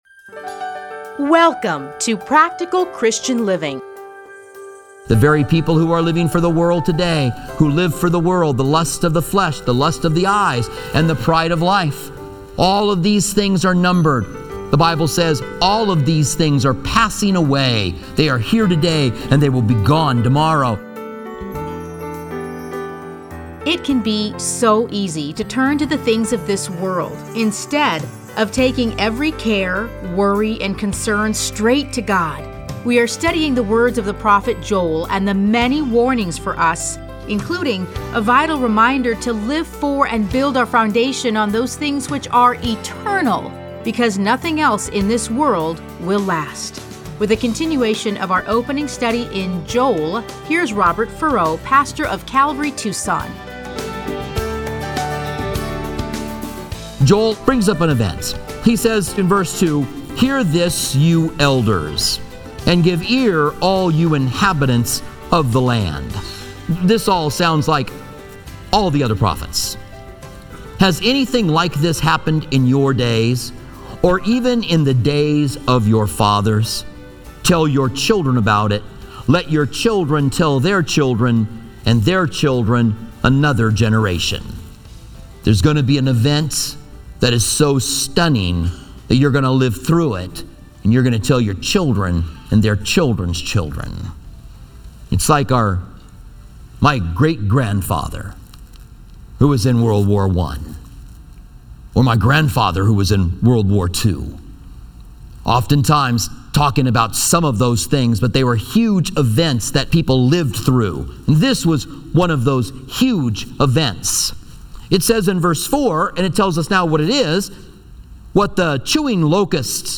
Listen to a teaching from Joel 1:1-12.